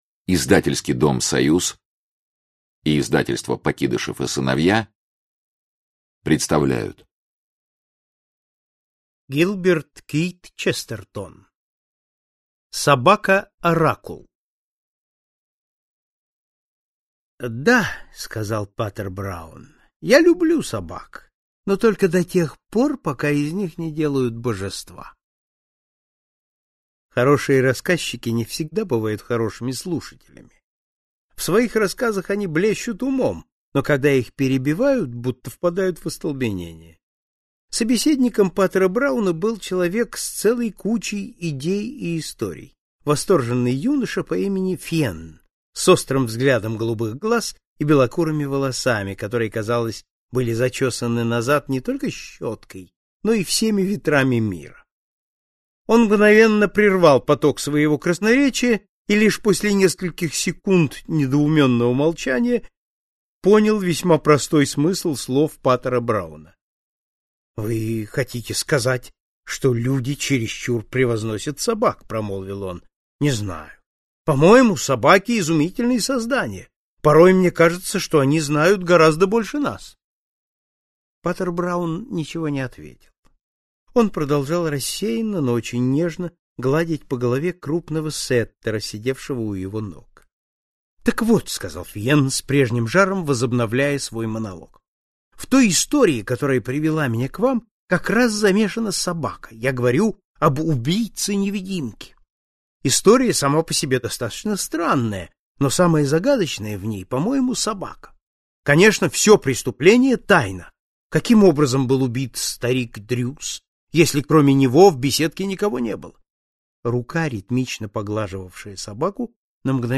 Аудиокнига Собака-оракул | Библиотека аудиокниг